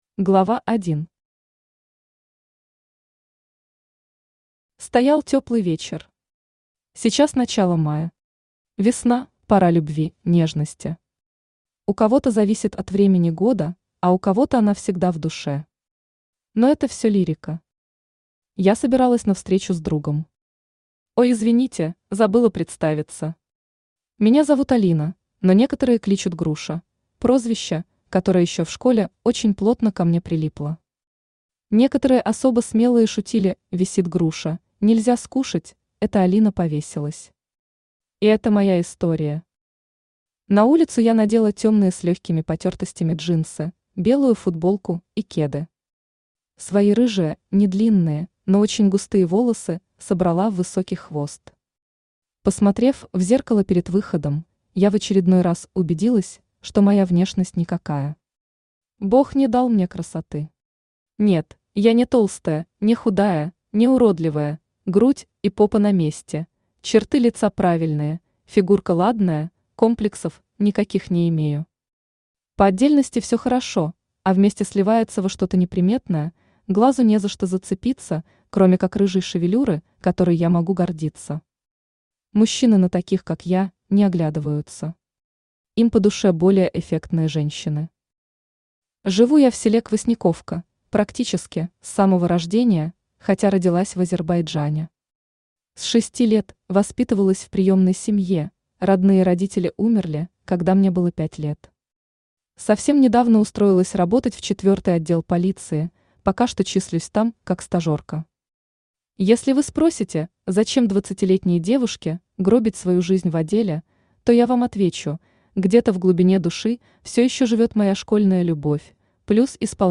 Aудиокнига My Story Автор Алина Владимировна Грушина Читает аудиокнигу Авточтец ЛитРес.